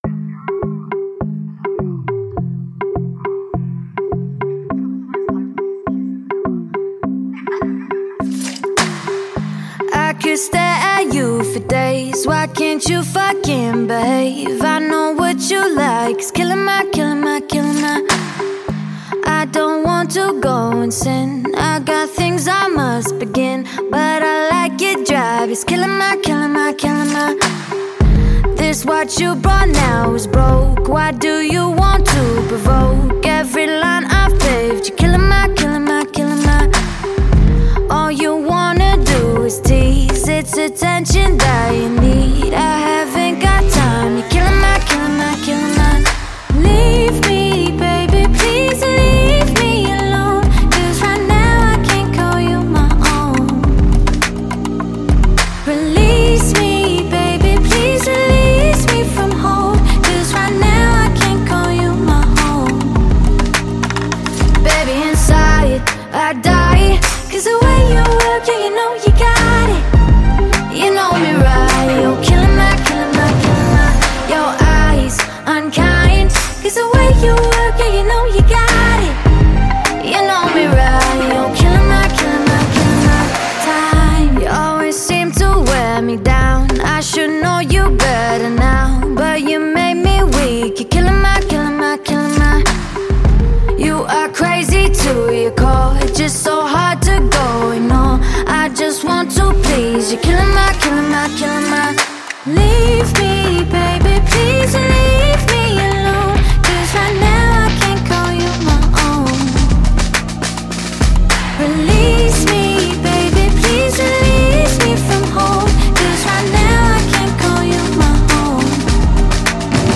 # Alternative